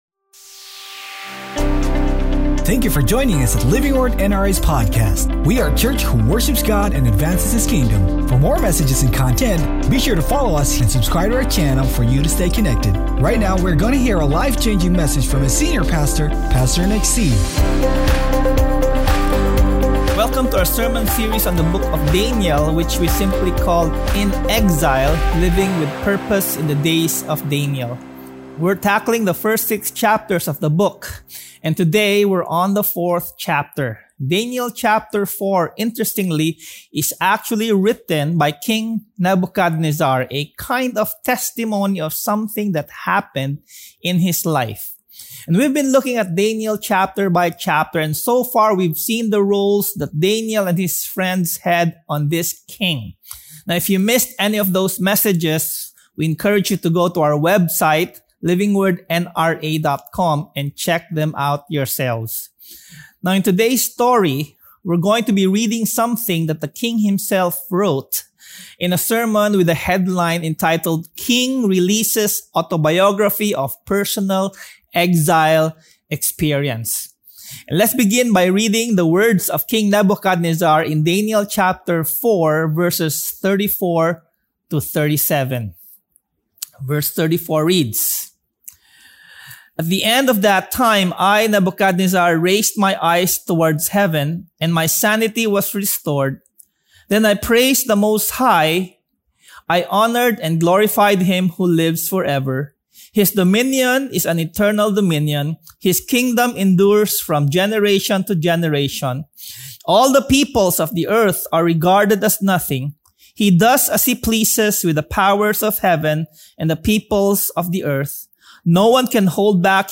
King-Releases-AUDIO-Sermon.mp3